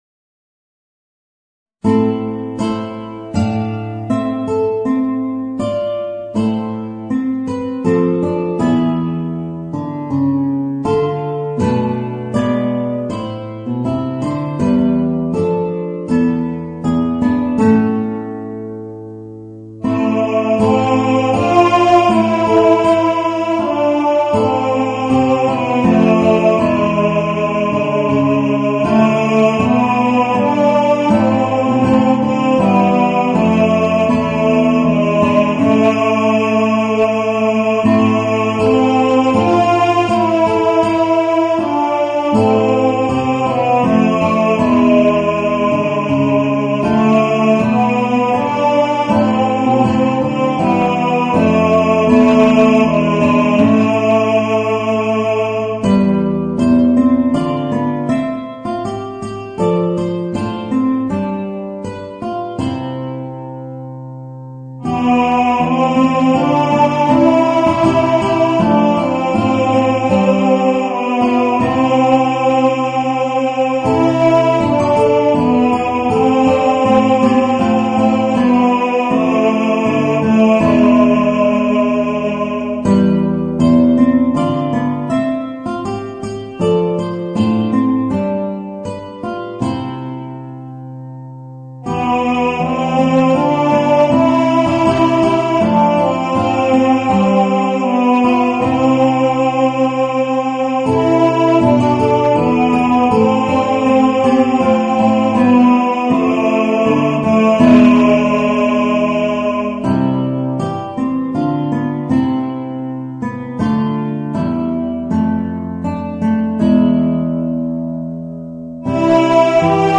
Voicing: Guitar and Baritone